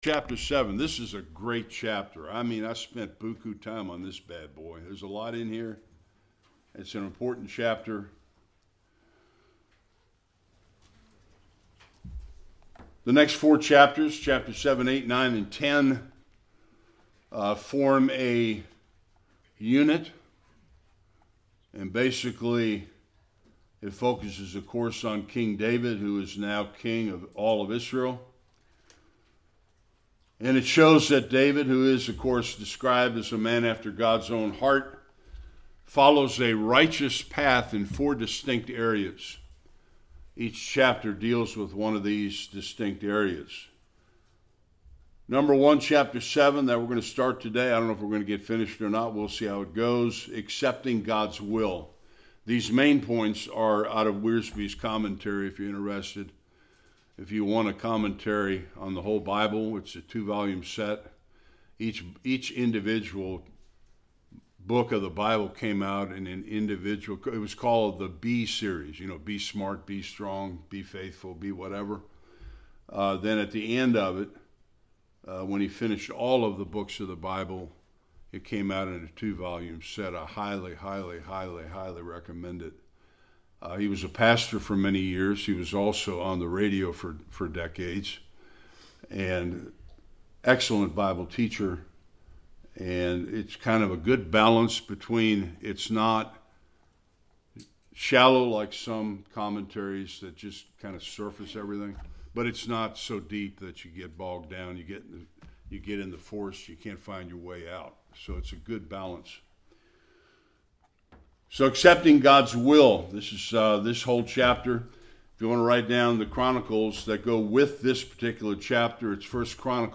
1-9 Service Type: Sunday School What the Davidic Covenant meant to King David.